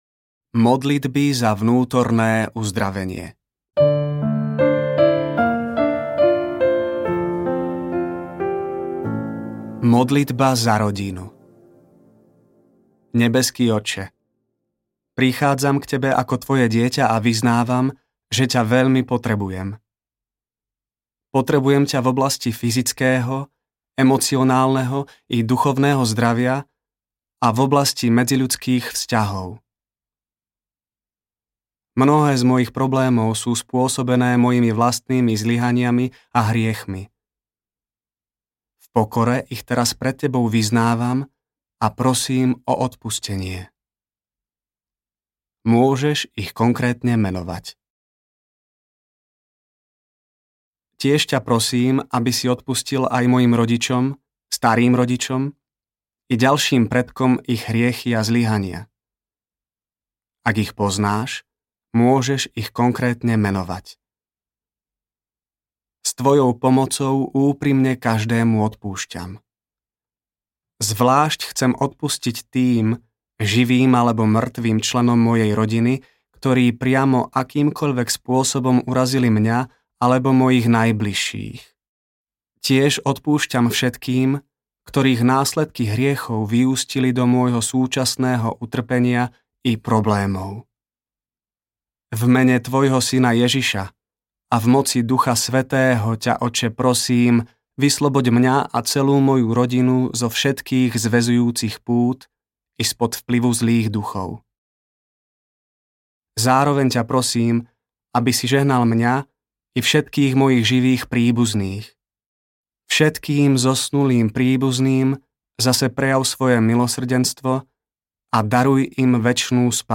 Pane, príď mi na pomoc audiokniha
Ukázka z knihy
pane-prid-mi-na-pomoc-audiokniha